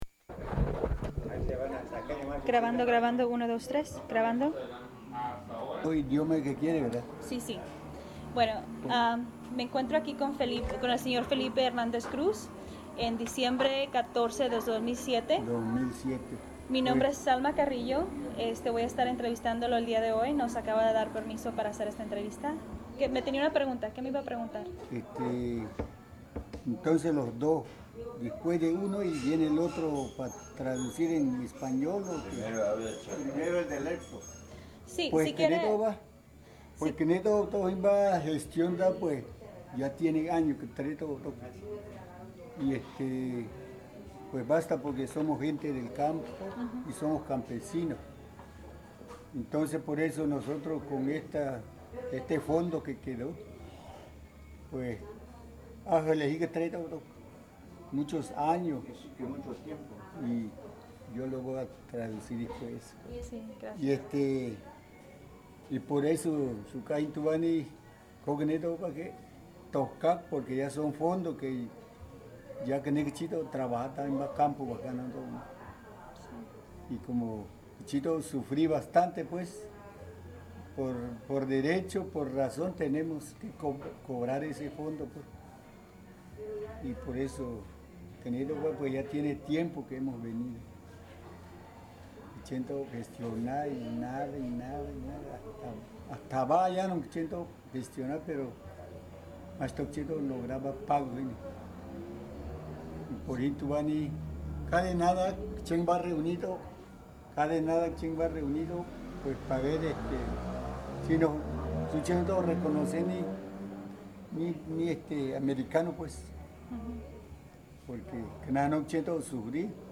Location Los Chontales de Tamulte, Tabasco